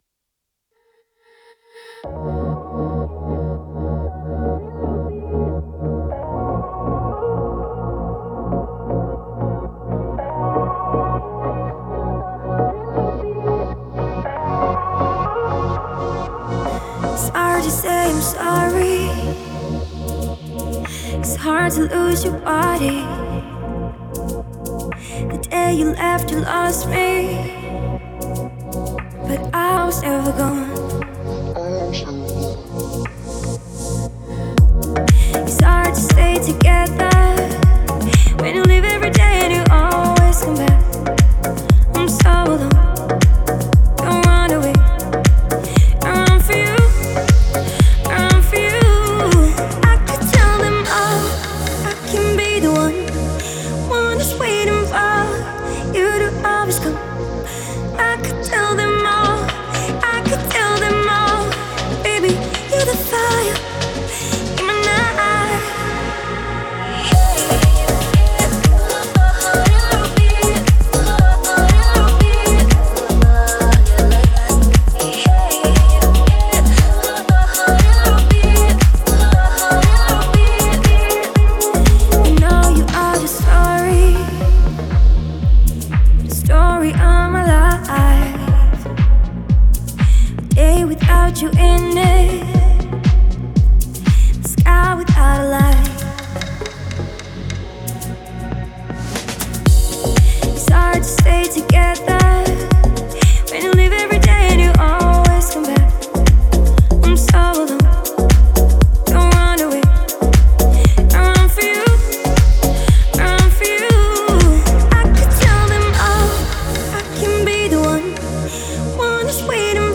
это яркая и эмоциональная песня в жанре поп